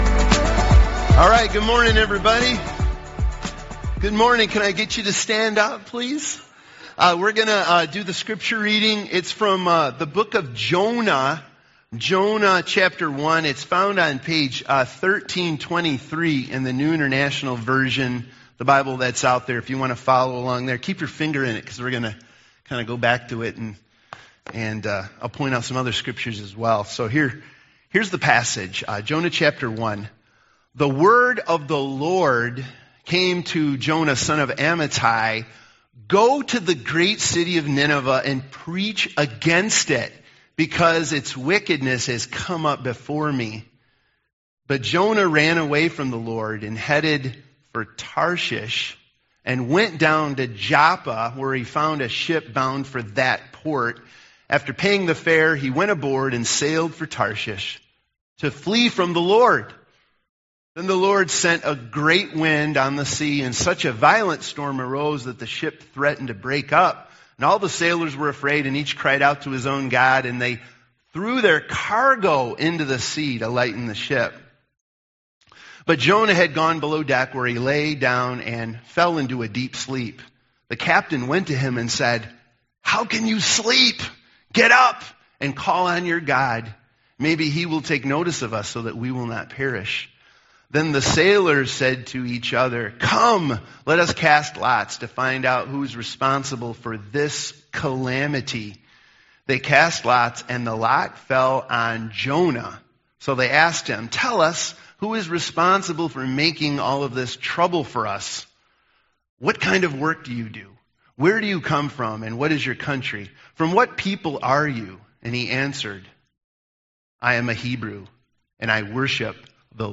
Jonah—The Scandal of Grace Service Type: Sunday Morning « Seeking God Together Lessons From The Deep